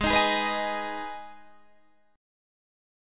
Abm Chord
Listen to Abm strummed